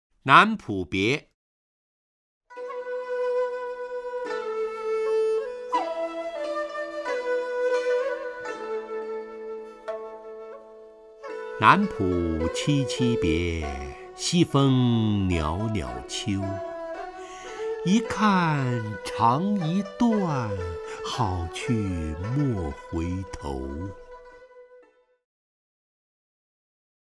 陈醇朗诵：《南浦别》(（唐）白居易) （唐）白居易 名家朗诵欣赏陈醇 语文PLUS